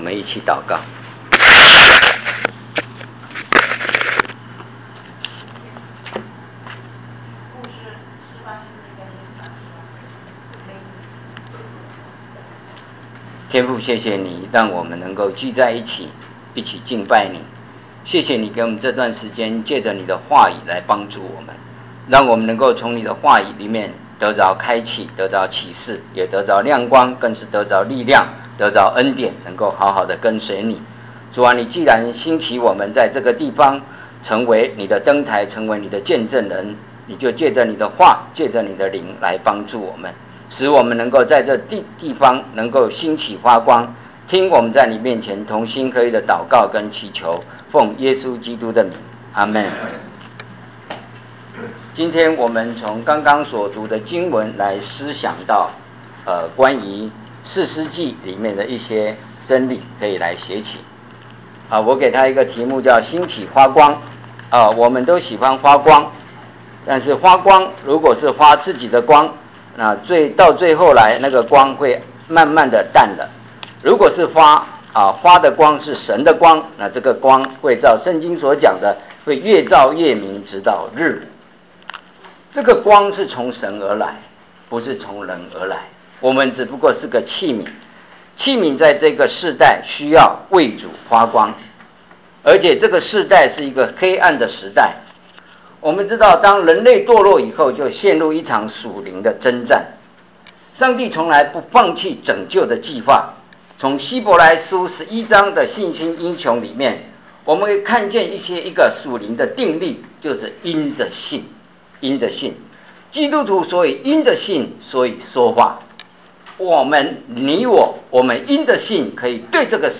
点击下图下载此Sermon音频文件(mp3, 32M)： Views: 162